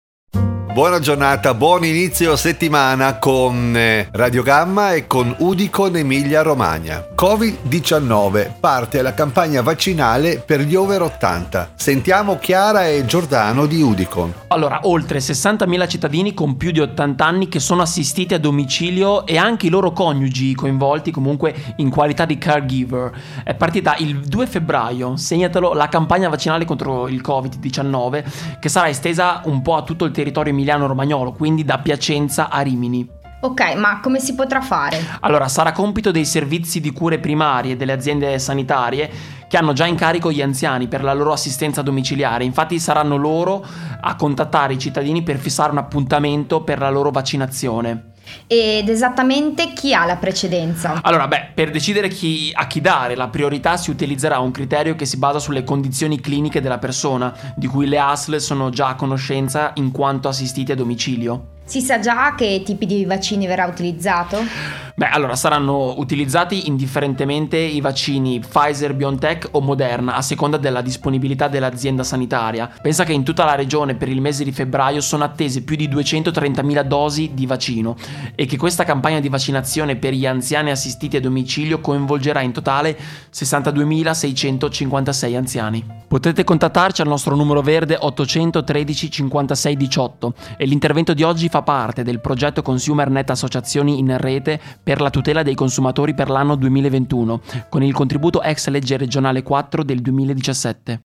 Intervento radiofonico su Radio Gamma del 08/02/2021